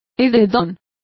Complete with pronunciation of the translation of quilt.